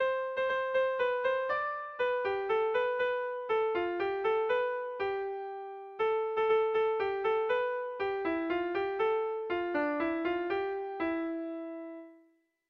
Dantzakoa
Lau puntuko berdina, 8 silabaz